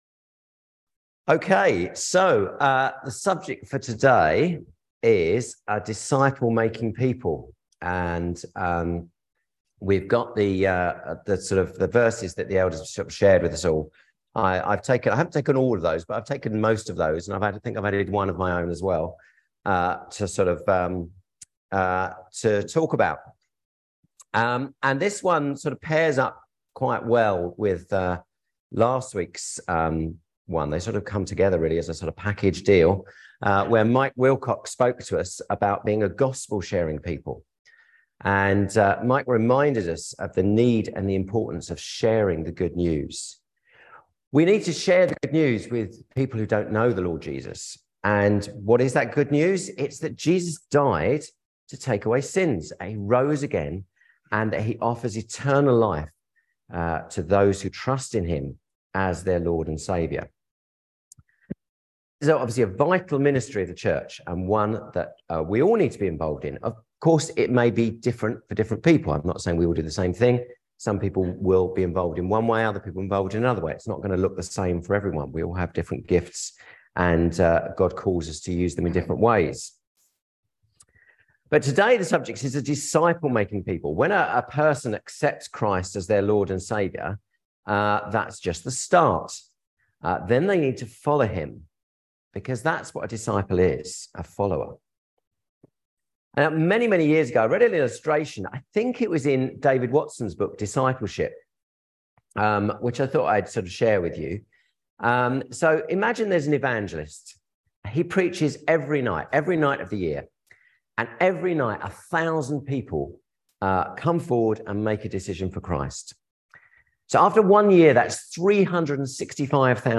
Passage: Matthew 28:18-20 Service Type: Sunday Service